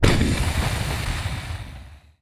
shatter.ogg